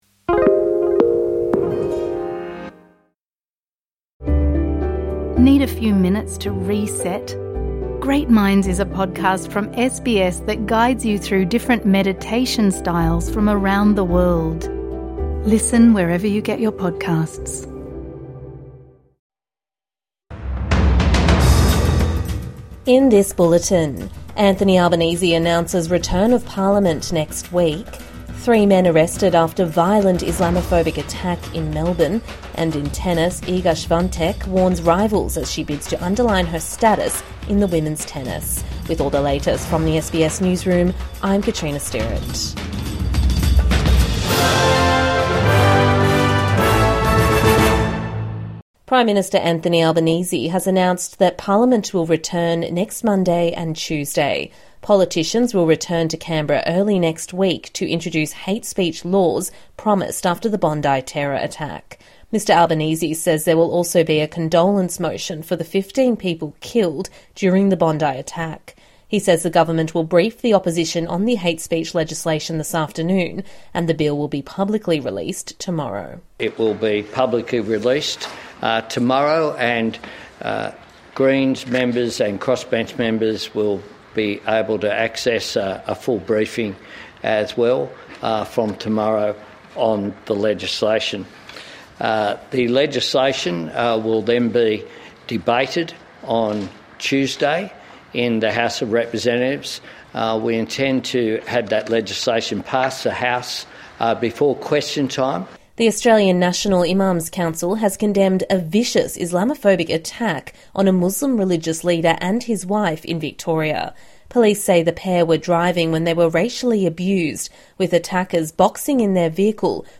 Three men arrested after violent Islamophobic attack in Melbourne | Evening News Bulletin 12 January 2026